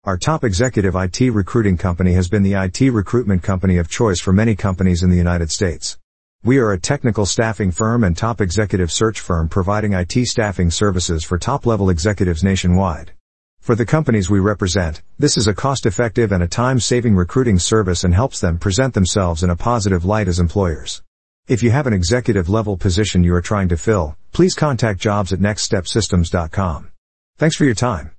Executive Search Employers, Listen to Our Artificial Intelligence (AI) on How Our Specialized IT Recruitment Services Can Help Your Company with Your Information Technology Executive Search
Please take a moment to listen to an audio file about our IT executive search recruitment services generated by Artificial Intelligence (AI).